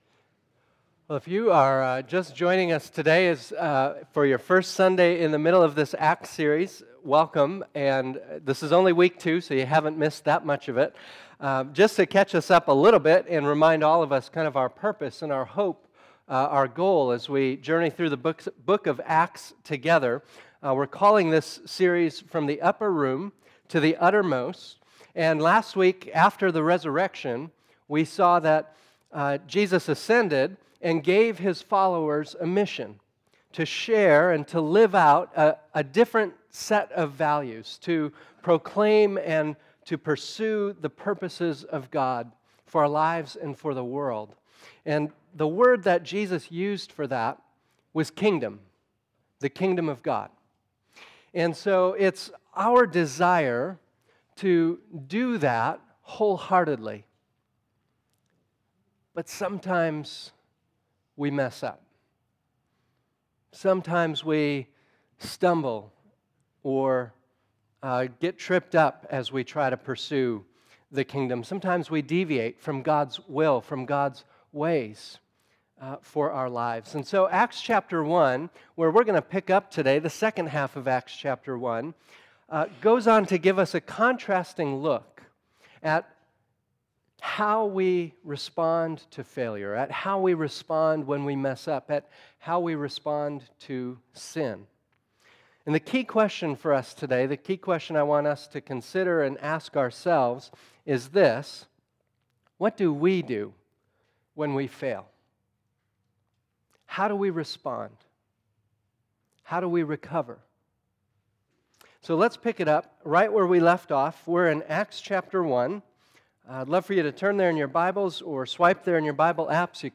Today we continue our sermon series, Acts: From the Upper Room to the Utter Most.